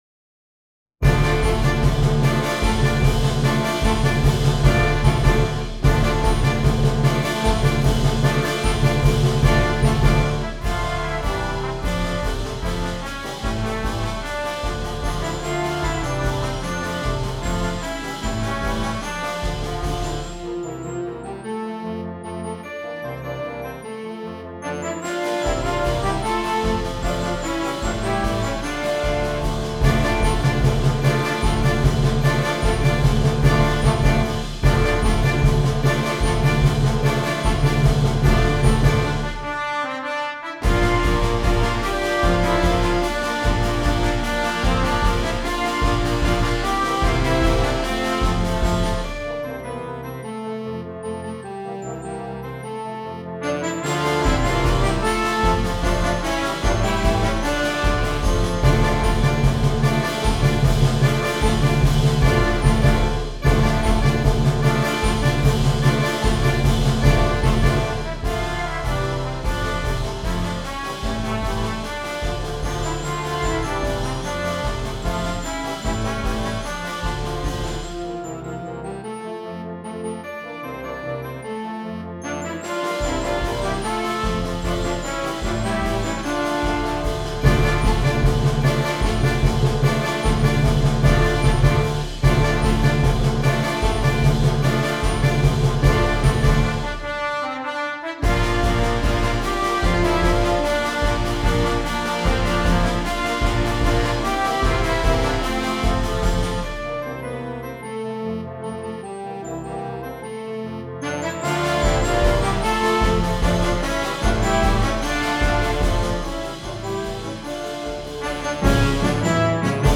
• Flauta
• Oboe
• Clarinete en Bb
• Clarinete Bajo
• Saxofón Alto
• Saxofón Tenor
• Saxofón Barítono
• Trompeta en Bb
• Corno en F
• Trombón
• Fagot
• Barítono (T.C)
• Tuba
• Timbal
• Glockenspiel
• Platillos
• Redoblante
• Bombo